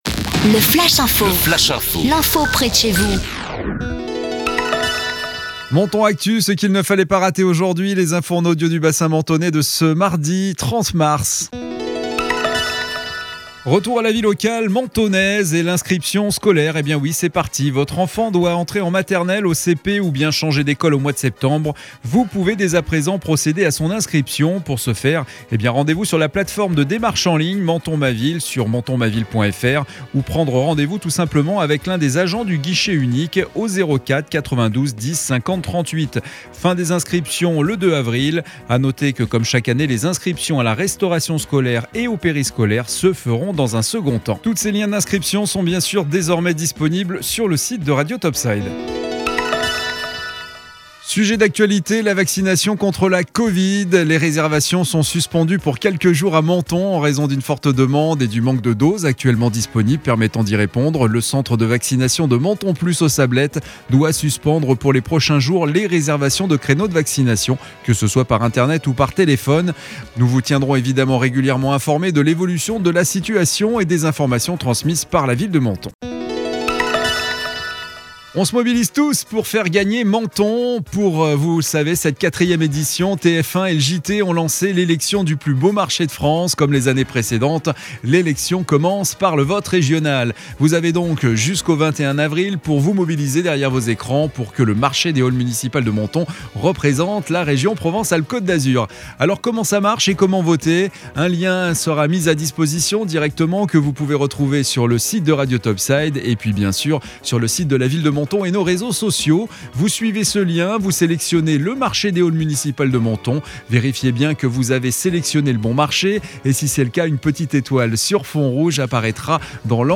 Menton Actu - Le flash info du mardi 30 mars 2021